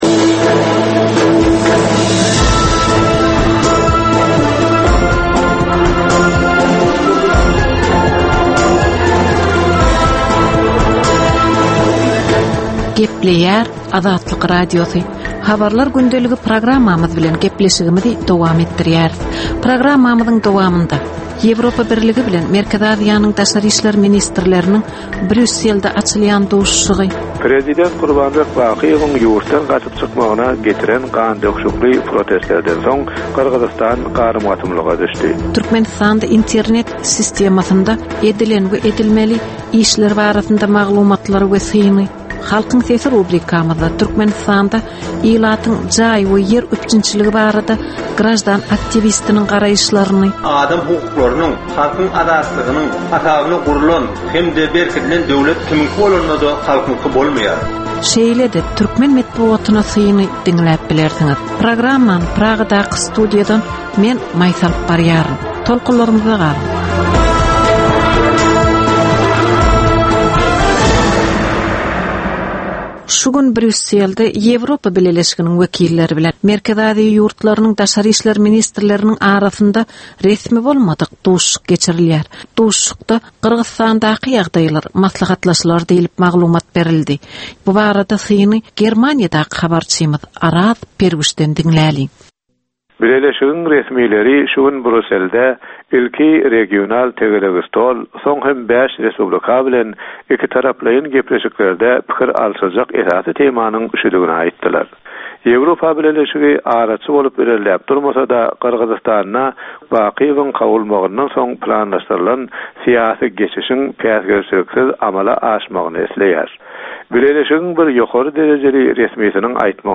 Bu programmada soňky möhüm wakalar we meseleler barada ginişleýin maglumatlar, analizler, synlar, makalalar, söhbetdeşlikler, reportažlar, kommentariýalar we diskussiýalar berilýär.